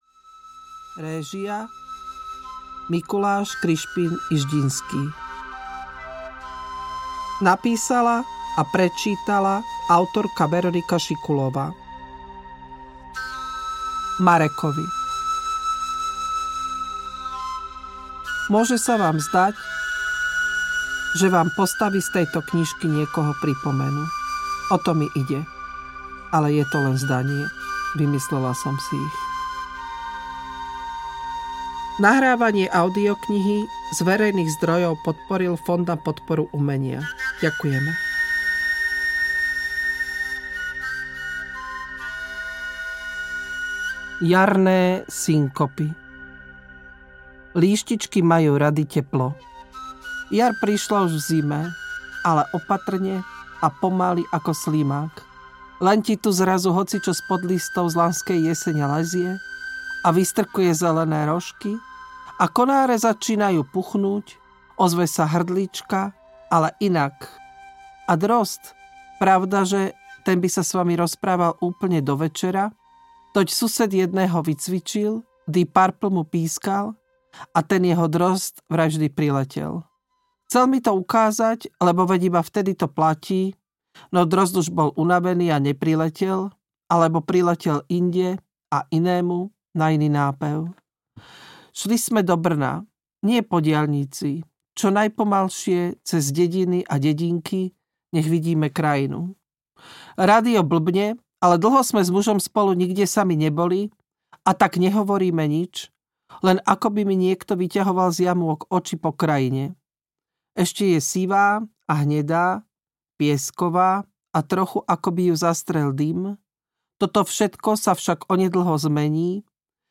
Líštičky majú rady teplo audiokniha
Ukázka z knihy